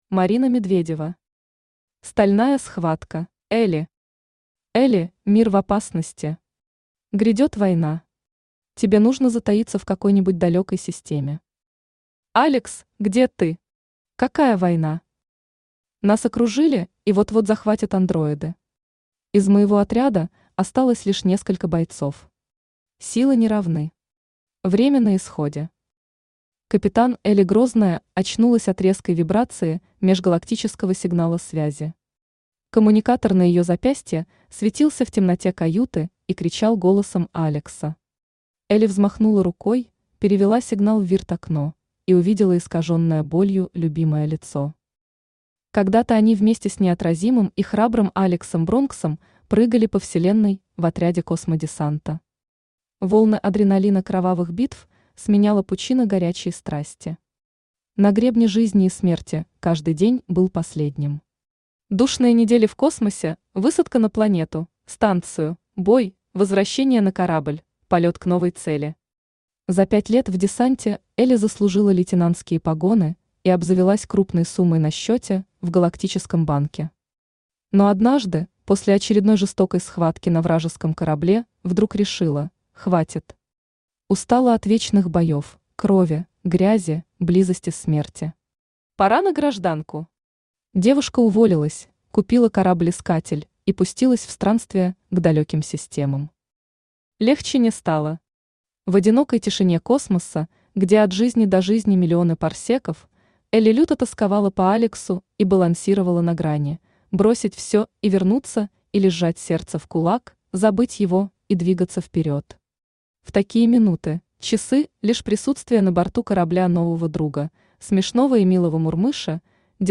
Аудиокнига Стальная схватка | Библиотека аудиокниг
Aудиокнига Стальная схватка Автор Марина Васильевна Медведева Читает аудиокнигу Авточтец ЛитРес.